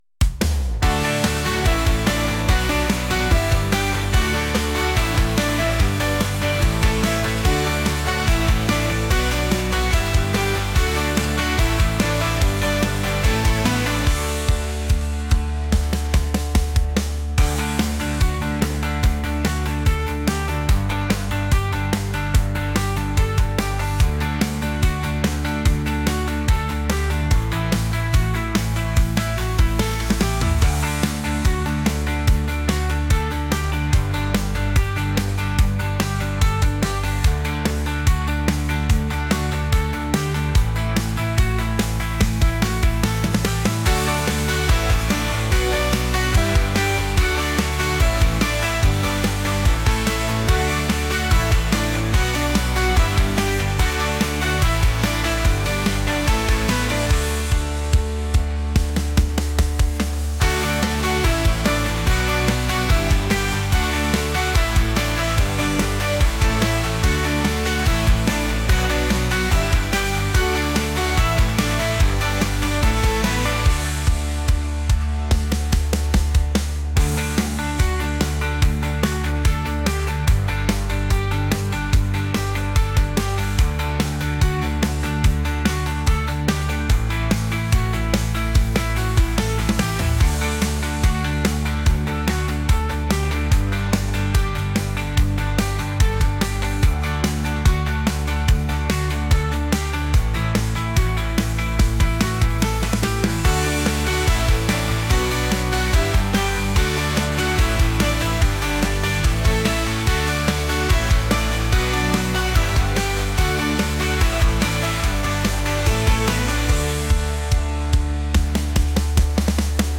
upbeat | catchy | pop